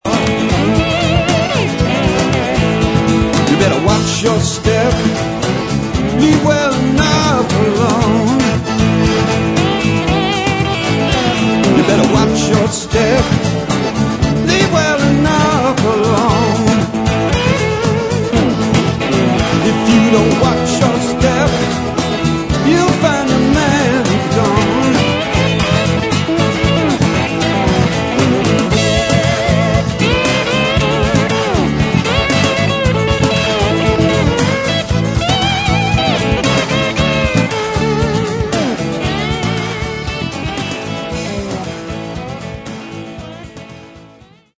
bass
keyboards
acoustic guitars